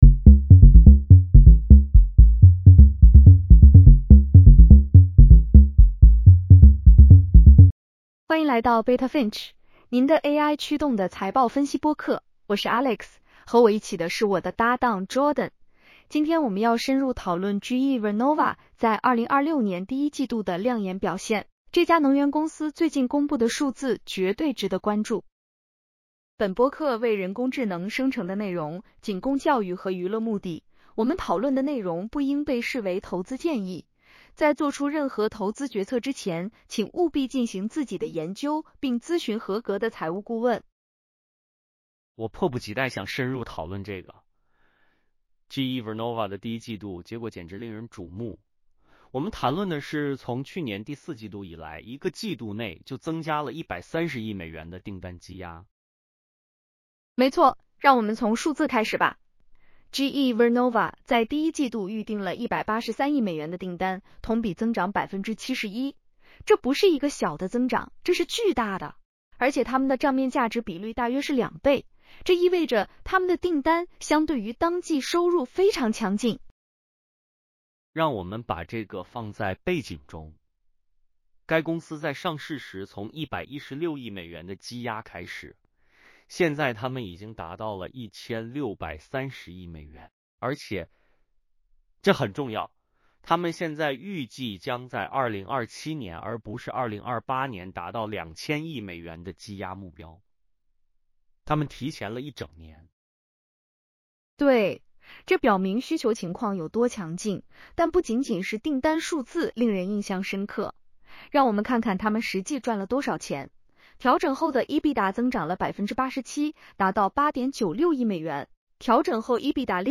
本播客为人工智能生成的内容，仅供教育和娱乐目的。